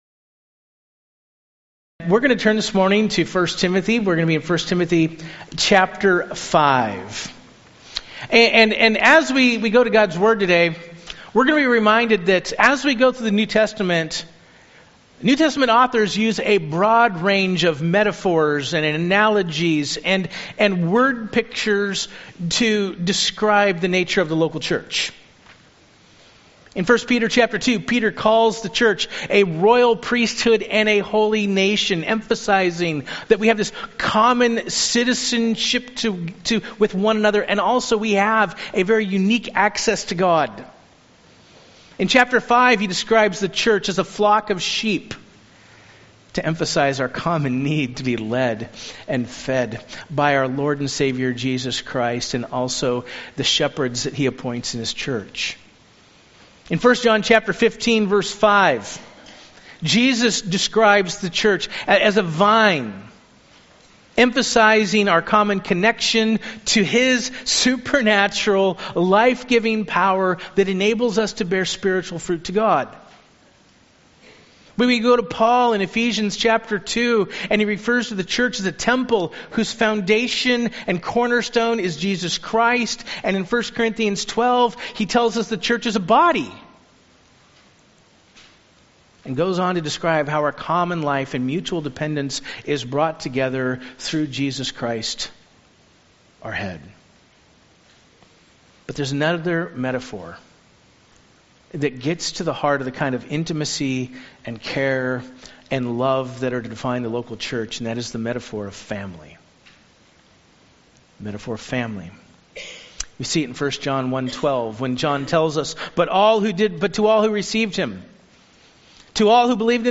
Sermon Outline: I. Confront sin without crushing the sinner (1 Timothy 5:1) II.